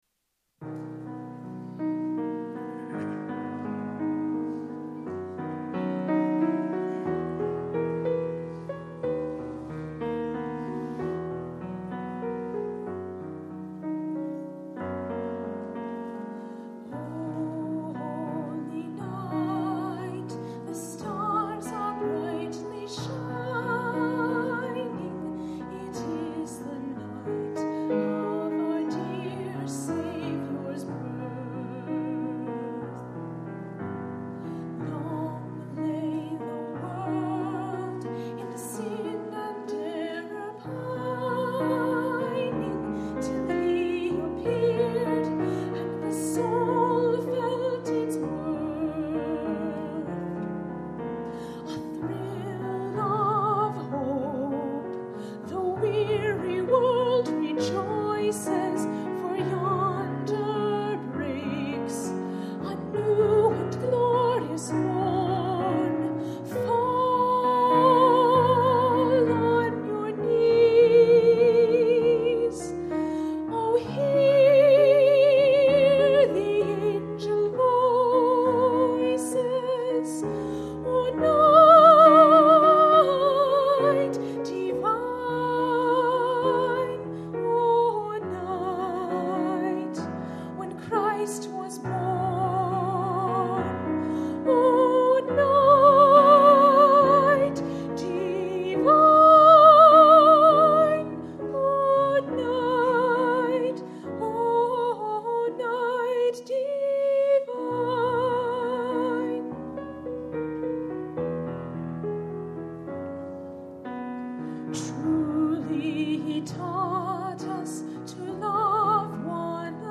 Special Music: O Holy Night: MP3